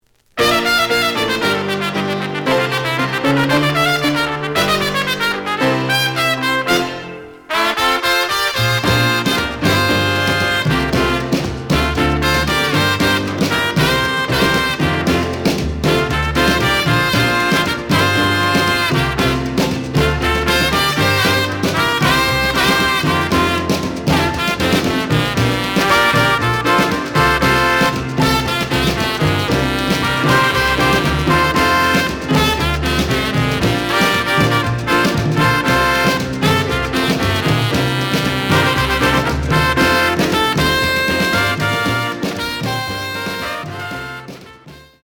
The audio sample is recorded from the actual item.
●Format: 7 inch
●Genre: Latin Jazz